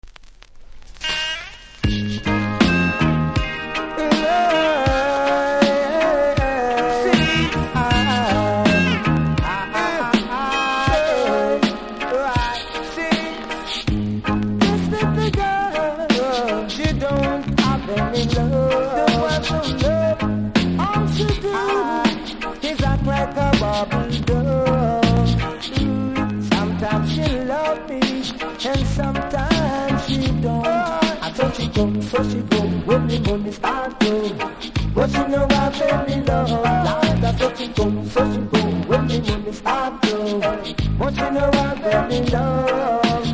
REGGAE 80'S